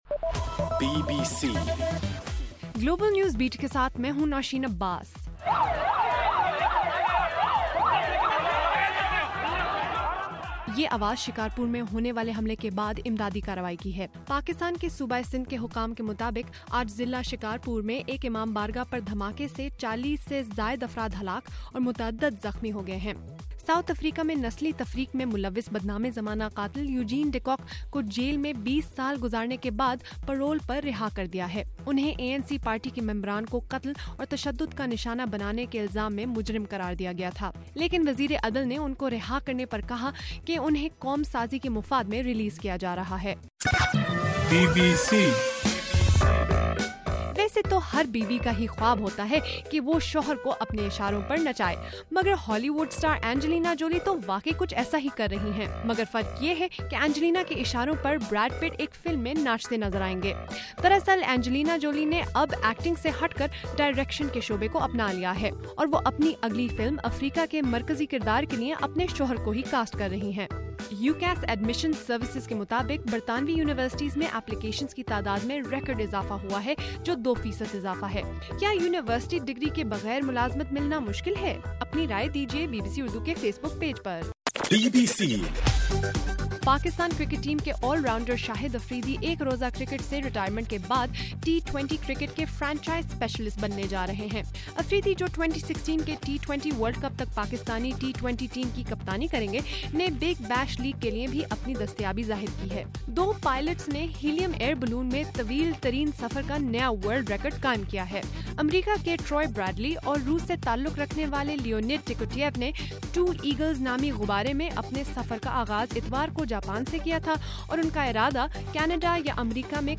جنوری 30: رات 8 بجے کا گلوبل نیوز بیٹ بُلیٹن